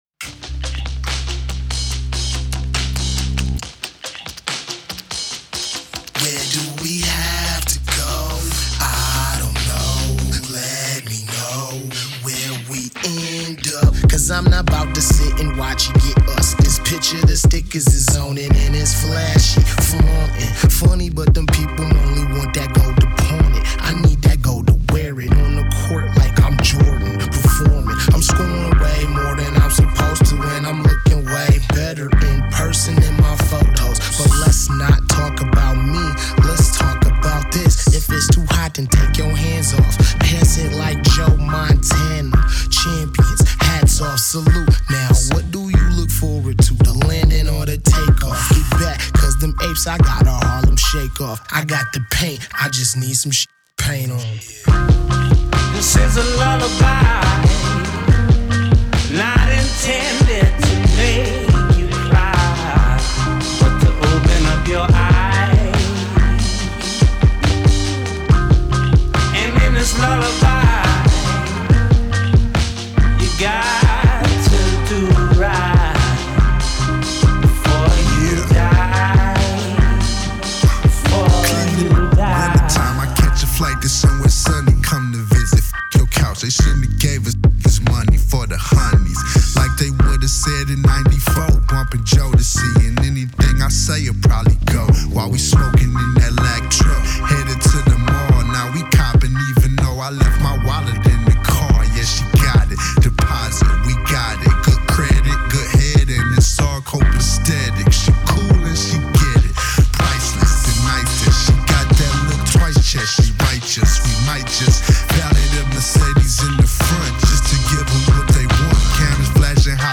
rapper
incredibly smooth jam